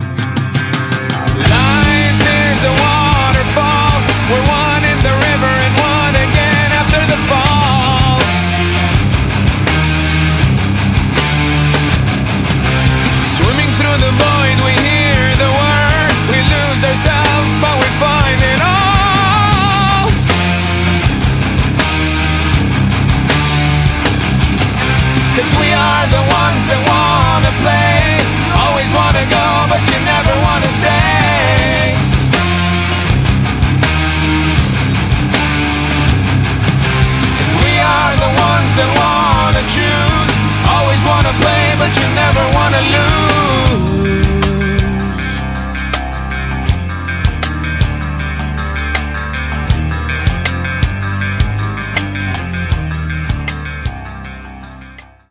• Качество: 128, Stereo
мужской вокал
громкие
Драйвовые
Alternative Metal
nu metal
Progressive Metal